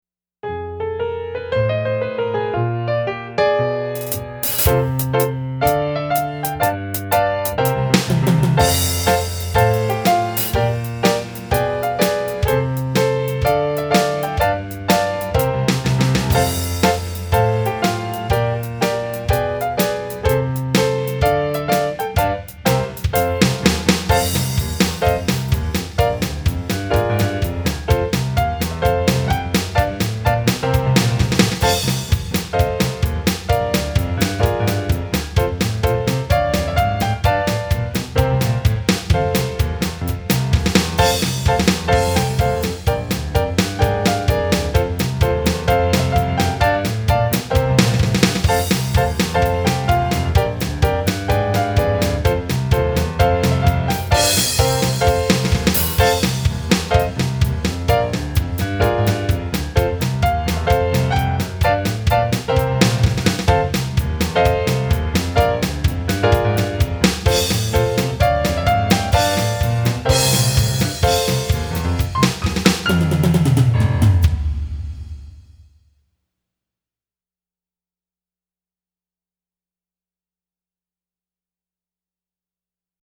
DIGITAL SHEET MUSIC -PIANO SOLO